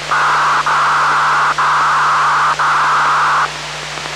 New Unidentified Signal